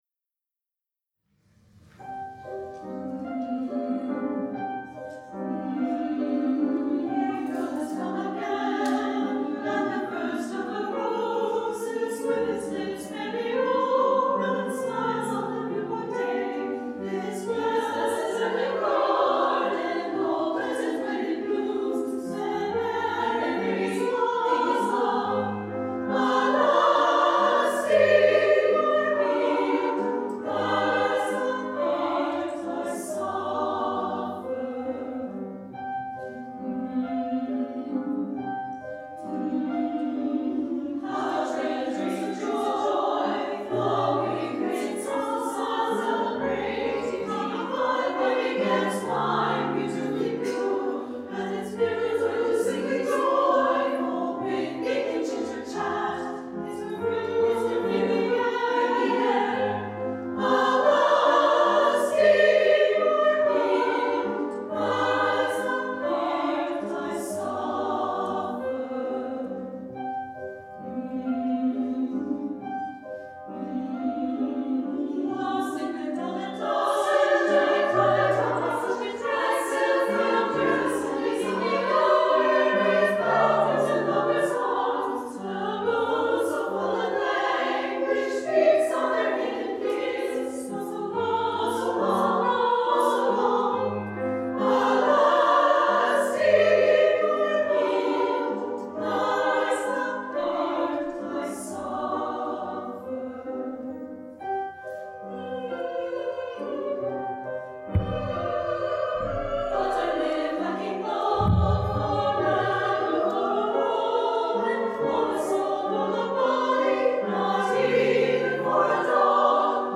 SSA + Piano 2’30”
SSA, Piano